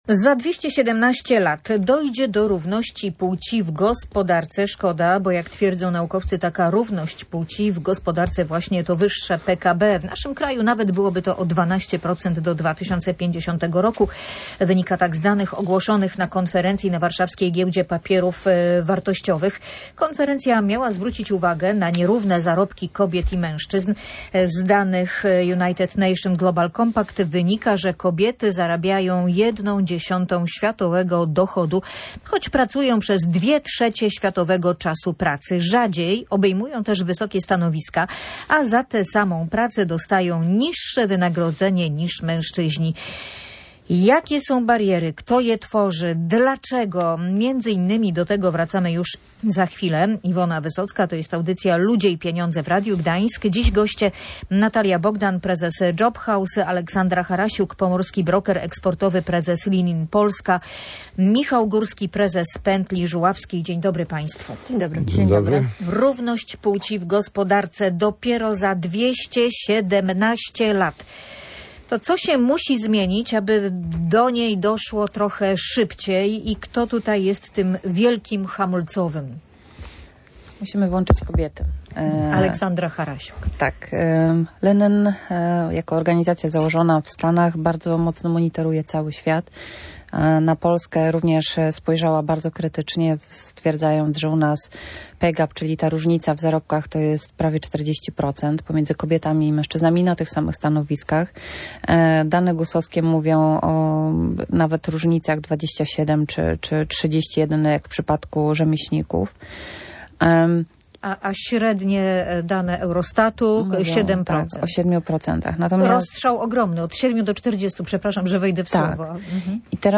Z danych United Nation Global Compact wynika, że kobiety rzadziej niż mężczyźni obejmują wysokie stanowiska, a za tę samą pracę otrzymują niższe wynagrodzenie Co musi się zmienić, żeby doszło do równości szybciej? Uczestnicy audycji dyskutowali między innymi o tym, że kobiety powinny być częściej zatrudniane, ale zauważa się, że rynek dla nich się kurczy.